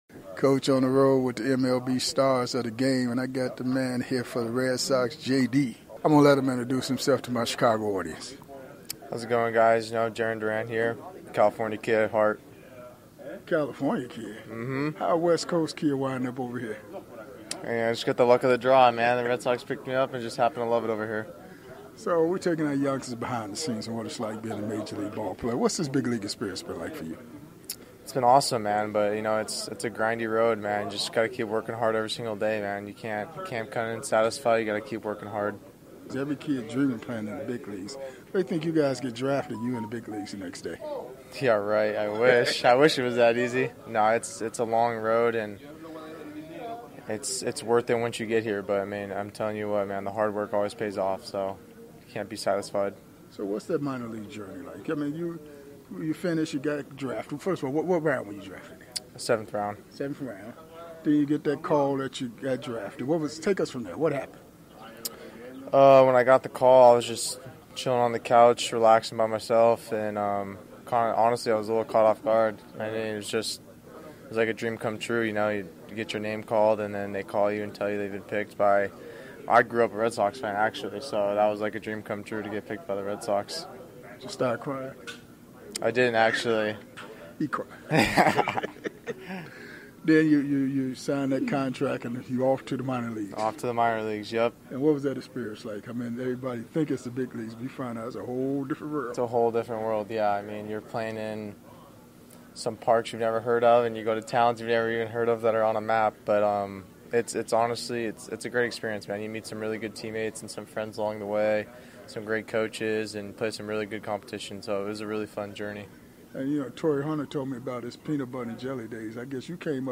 one on one with the MLB stars of the game